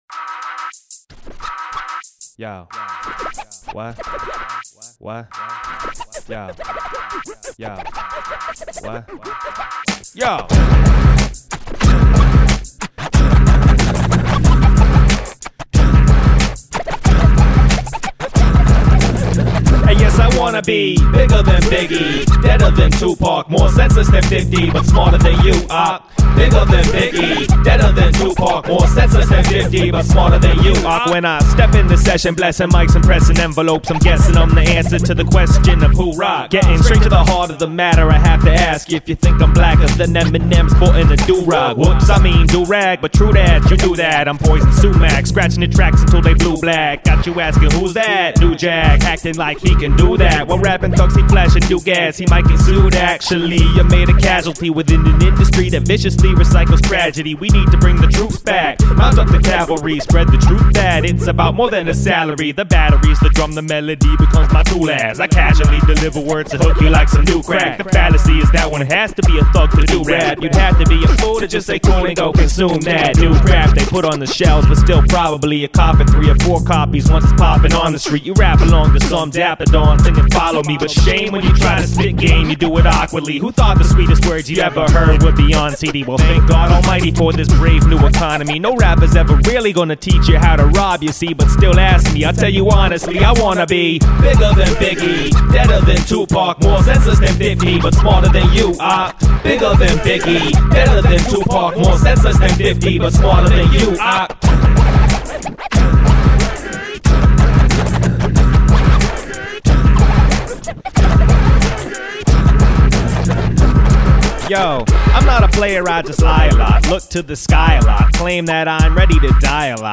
This track definitely has his most mainstream hip-hop feel